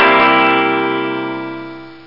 Guitar Reverb Sound Effect Download
Guitar Reverb Sound Effect
Download a high-quality guitar reverb sound effect.
guitar-reverb.mp3